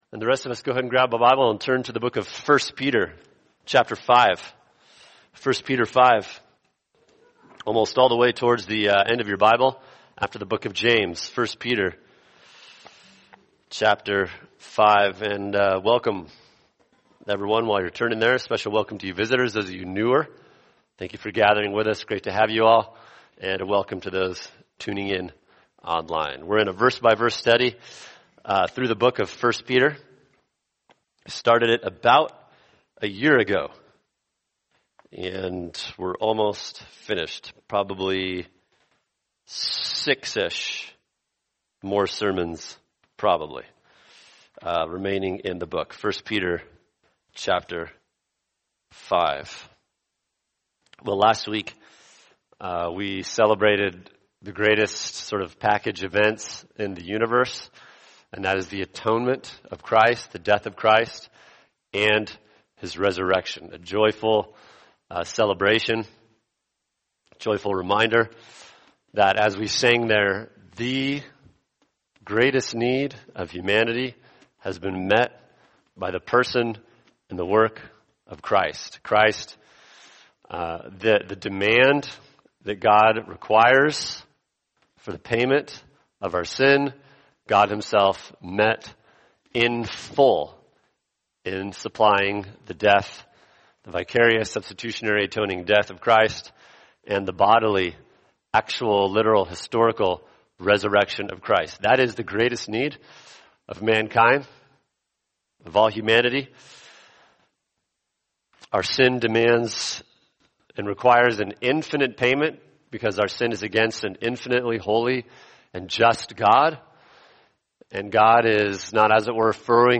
[sermon] 1 Peter 5:1-4 The Kind of Shepherding God Wants For Us | Cornerstone Church - Jackson Hole